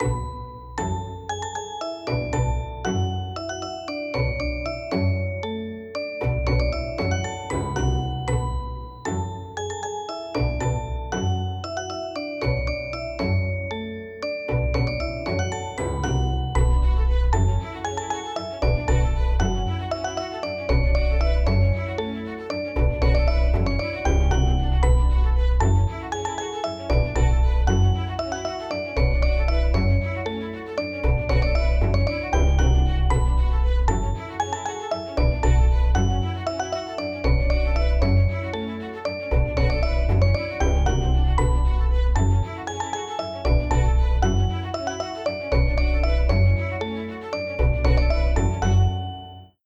怖い・不穏
【ループ＆バリエーション版あり】ちょっぴりホラー？で不思議な雰囲気のBGMです◎
▼静かめ部分のみループ版